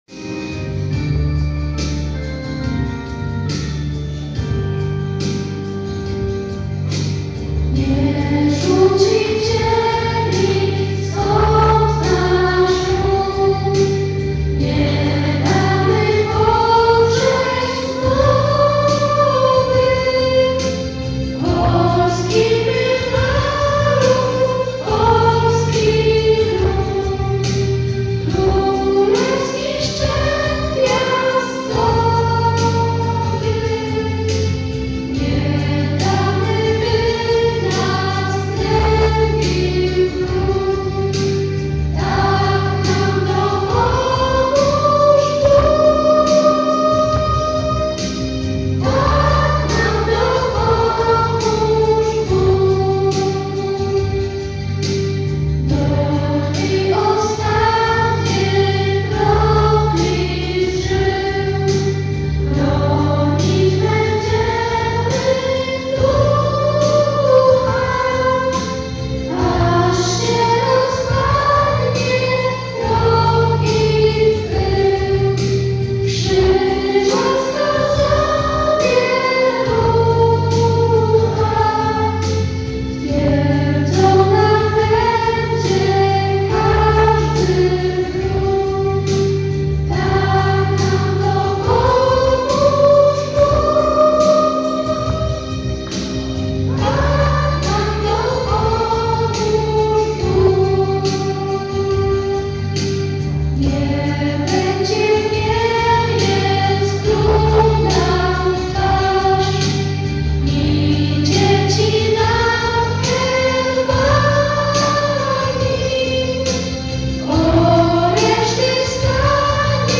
Dziękujemy również uczennicom, które przepięknie zaśpiewały piosenki.